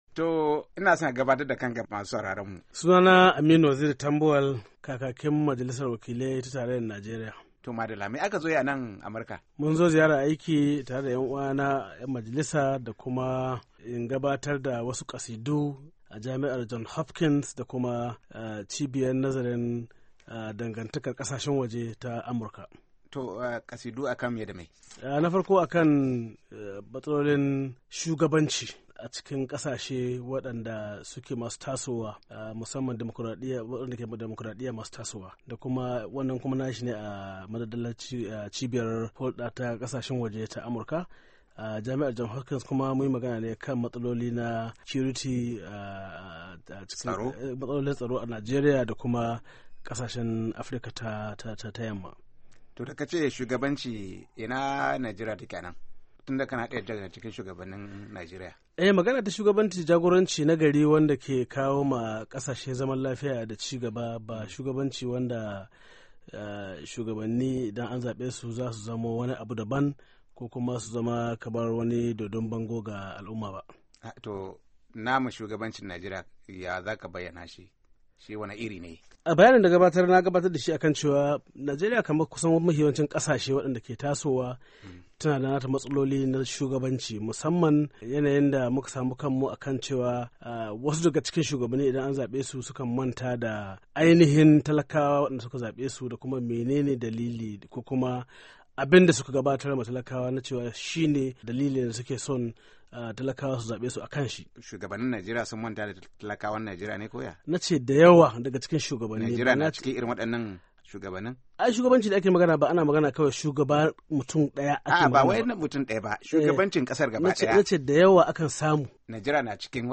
A firar da ya yi da Muryar Amurka kakakin majalisar wakilan Najeriya ya ce shugabanci nagari ke kawo zaman lafiya cikin kasa.